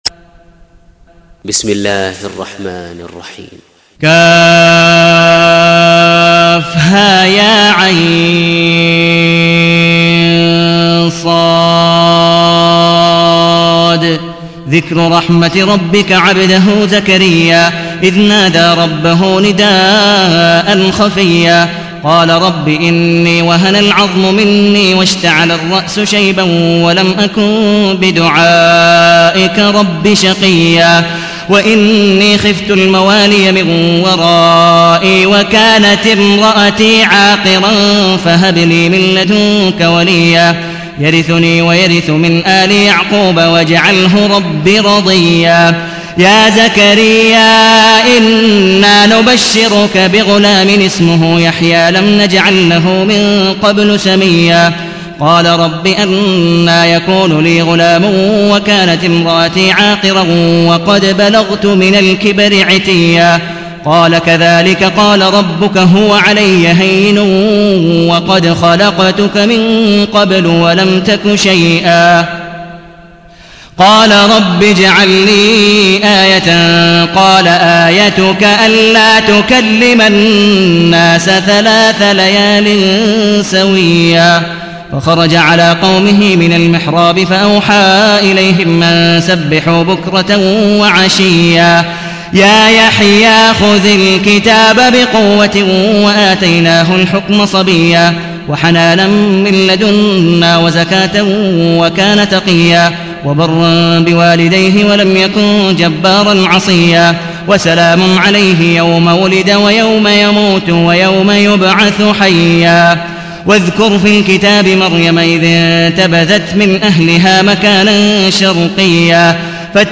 تحميل : 19. سورة مريم / القارئ عبد الودود مقبول حنيف / القرآن الكريم / موقع يا حسين